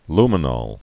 (lmə-nôl, -nŏl)